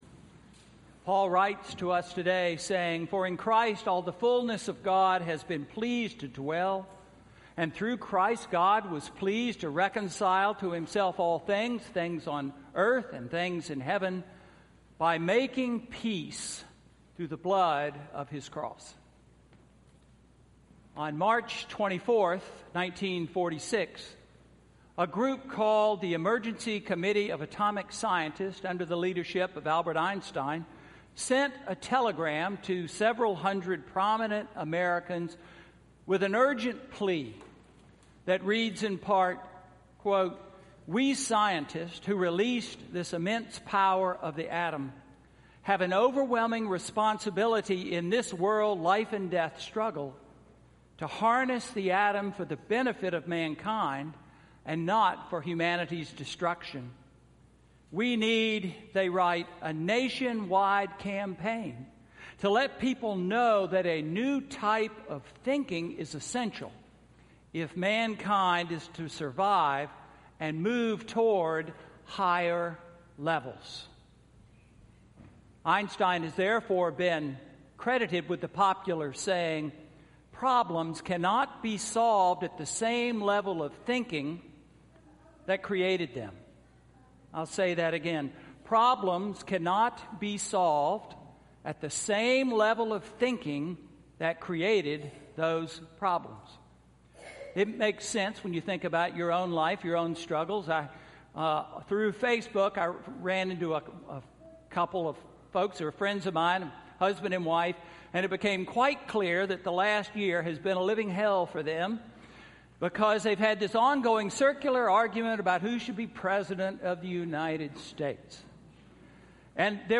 Sermon–Christ the King Sunday–November 20, 2016